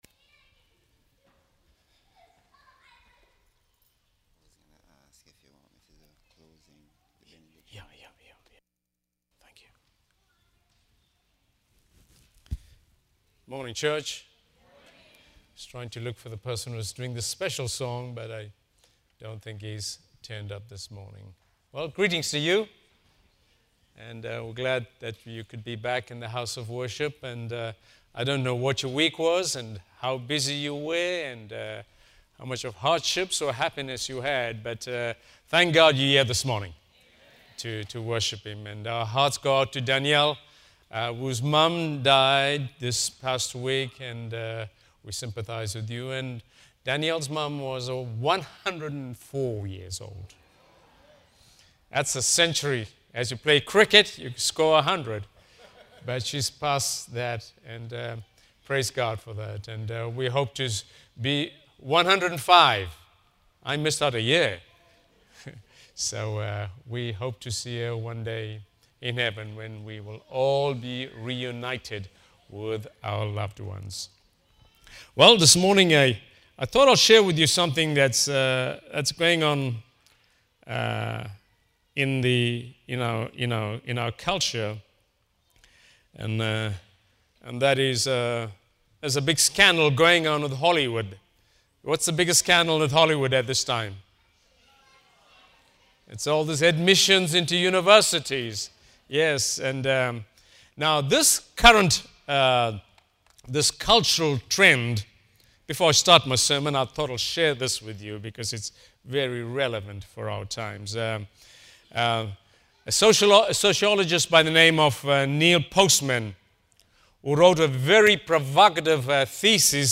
Service Type: Sabbath Worship